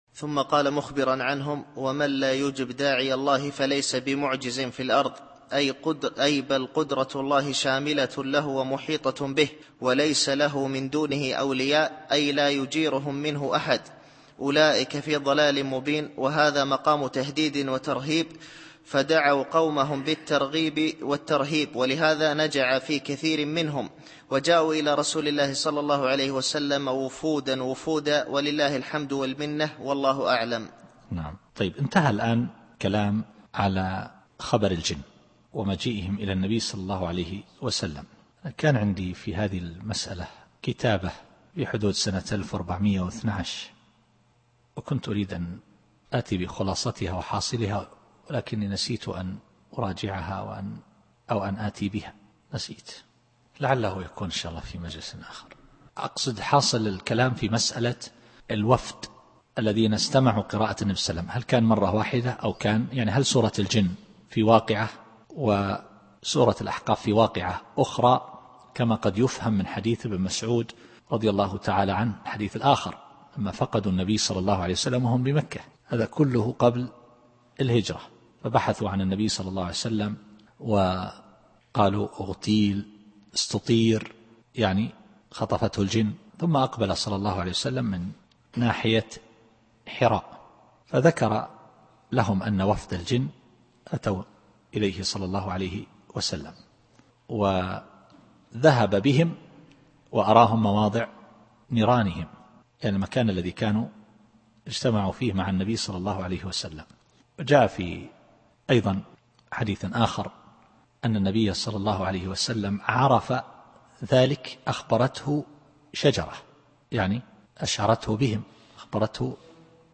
التفسير الصوتي [الأحقاف / 32]